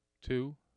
1. Spectrogram and AIF tracks for speech utterance “